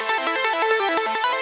SaS_Arp05_170-A.wav